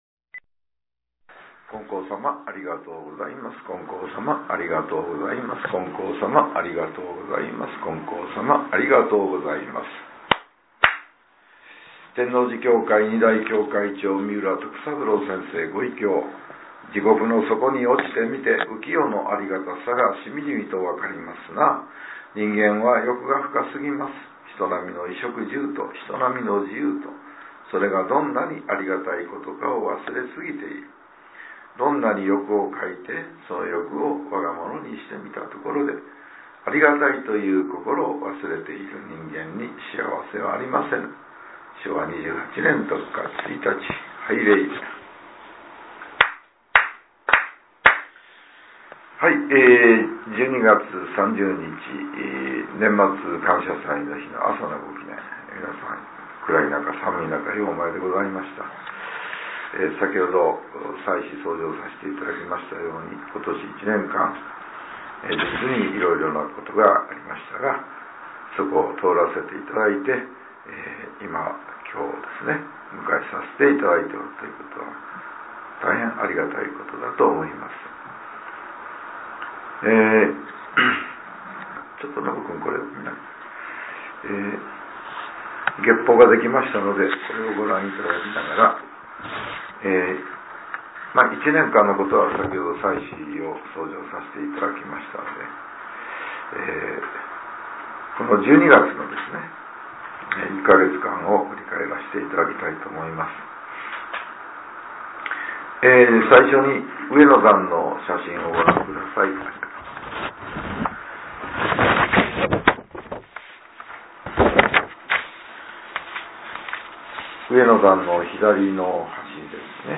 令和７年１２月３０日（朝）のお話が、音声ブログとして更新させれています。 きょうは、前教会長による「１２月を振り返り」です。